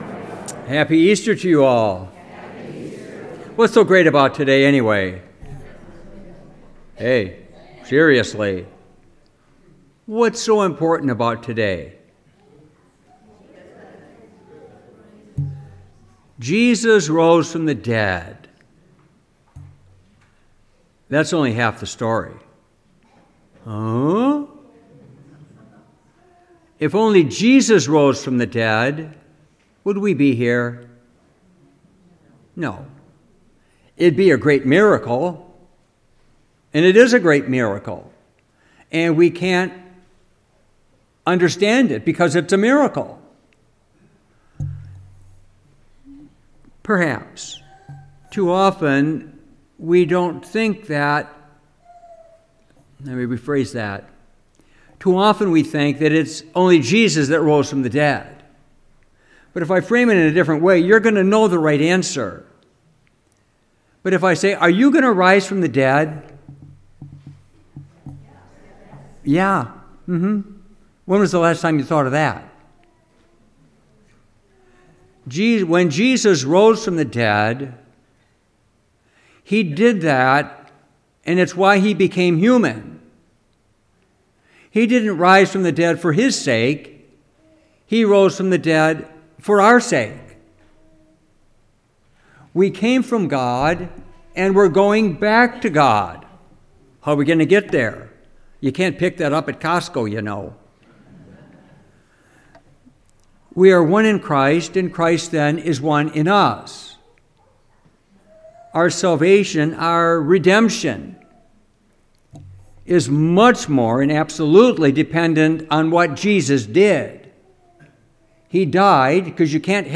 Homily, Easter 2025
Homily-Easter-Sunday-25.mp3